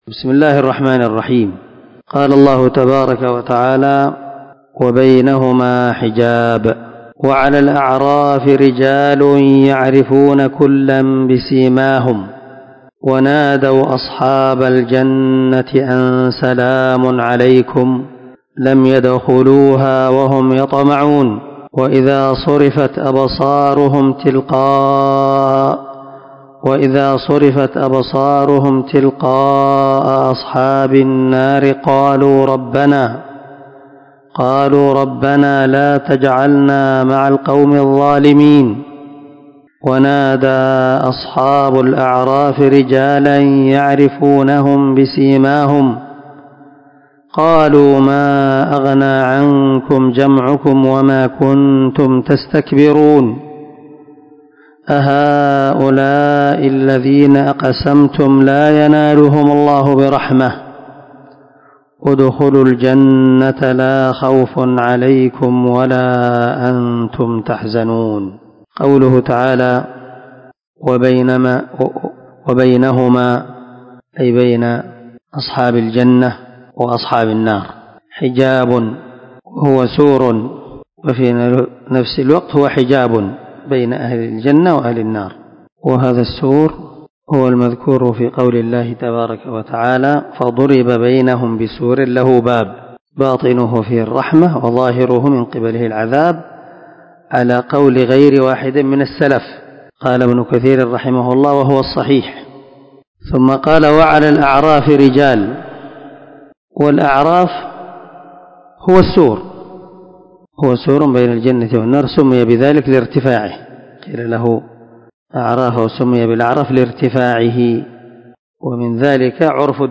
464الدرس 16 تفسير آية ( 46 – 49 ) من سورة الأعراف من تفسير القران الكريم مع قراءة لتفسير السعدي
دار الحديث- المَحاوِلة- الصبيحة.